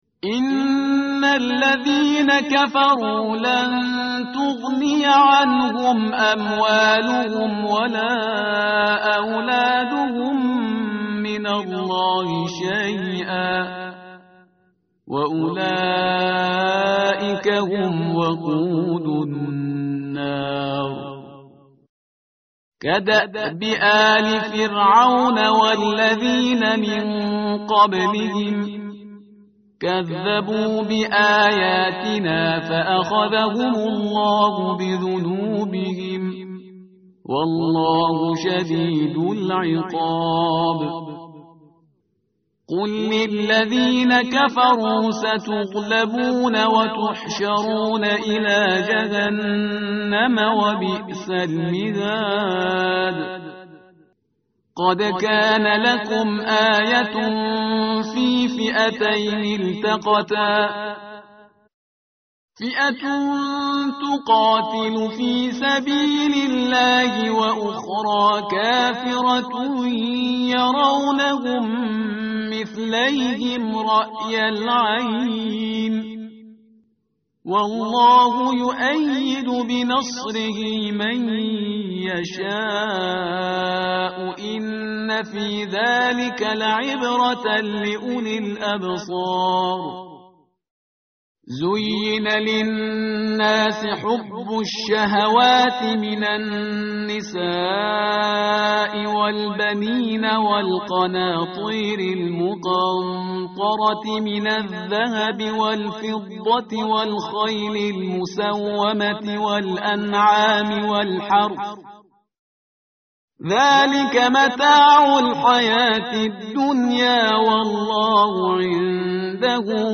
متن قرآن همراه باتلاوت قرآن و ترجمه
tartil_parhizgar_page_051.mp3